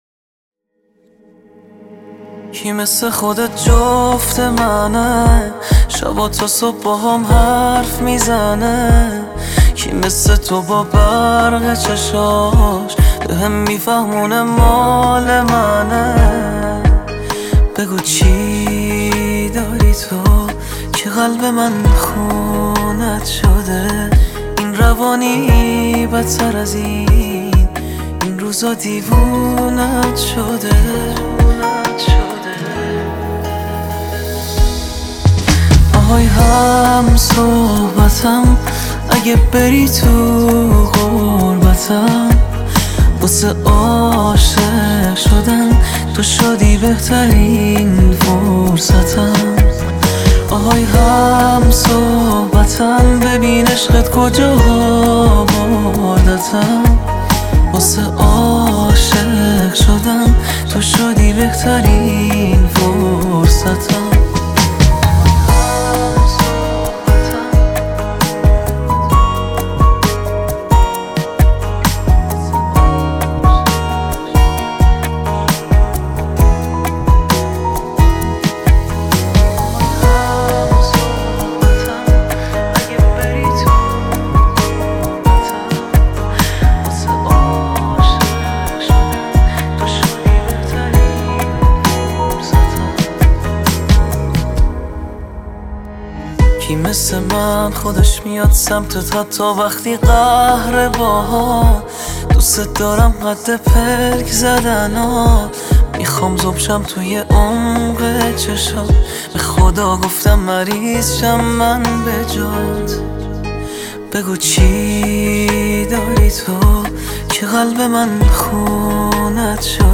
آهنگ عاشقانه